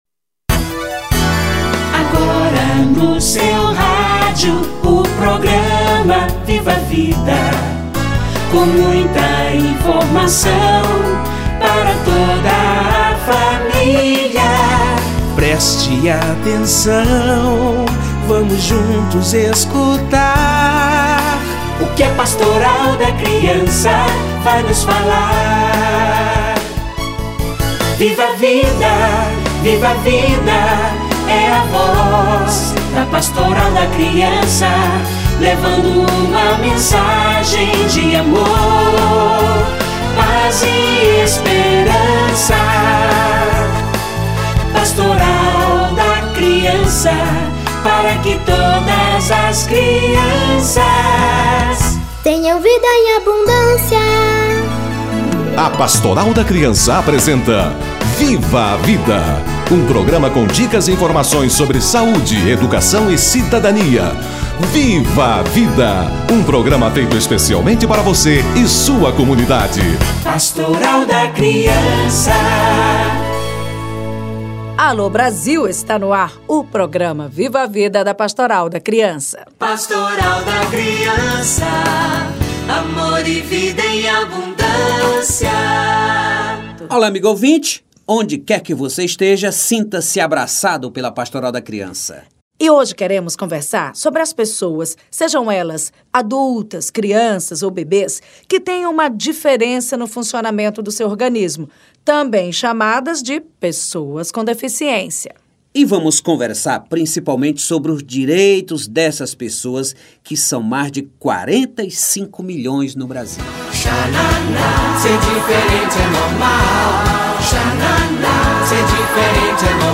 Crianças com diferenças no funcionamento de seu organismo - Entrevista